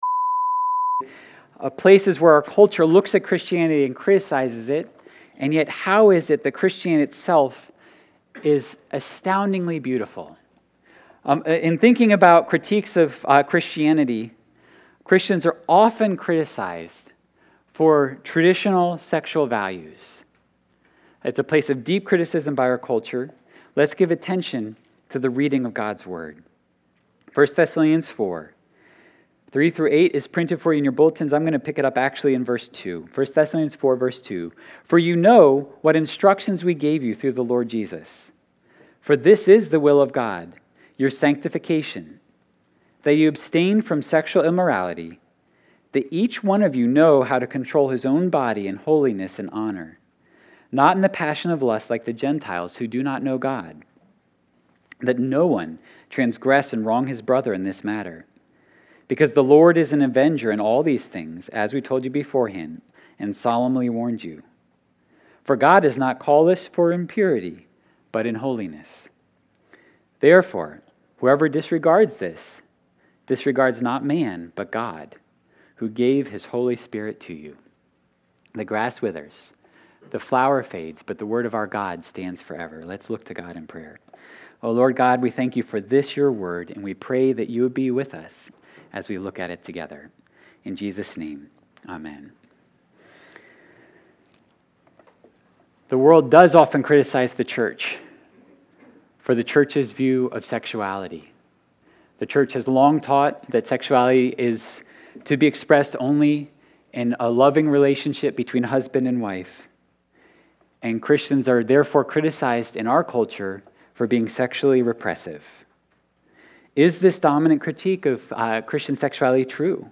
7.24.22-sermon-audio.mp3